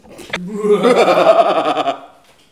rire-machiavelique_02.wav